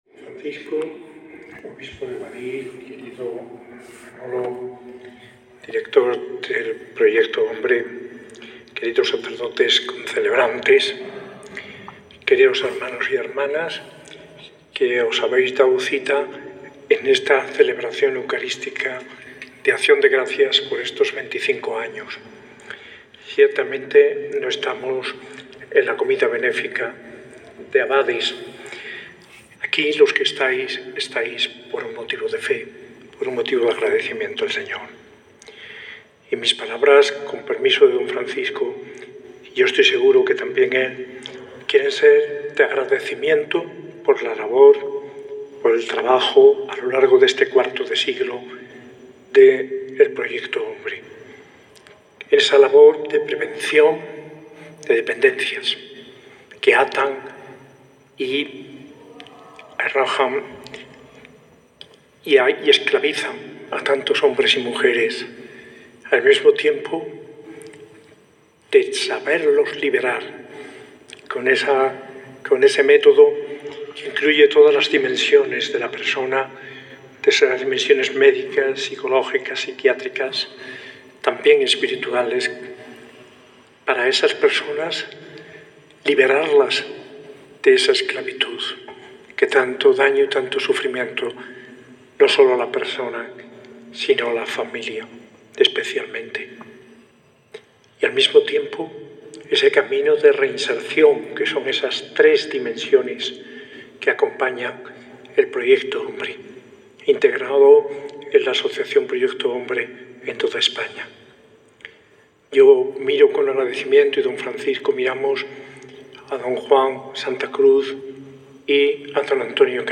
Homilía de Mons. José María Gil Tamayo, arzobispo de Granada, en la Eucaristía de acción de gracias de Proyecto Hombre Granada por su XXV aniversario desde su fundación, celebrada en la iglesia parroquial del Sagrario-Catedral el 30 de junio de 2025, y concelebrada por el obispo de Guadix, Mons. Francisco Jesús Orozco Mengíbar.